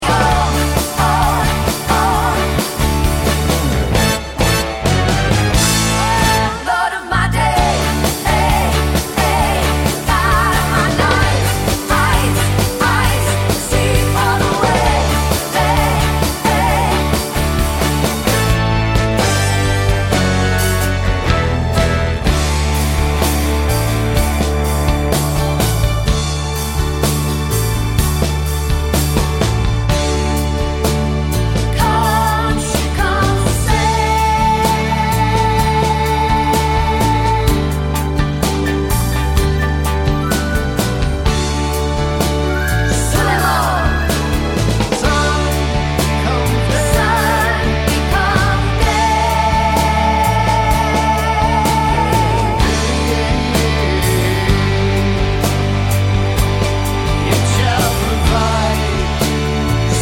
Cut Down Live Version Pop (1970s) 3:34 Buy £1.50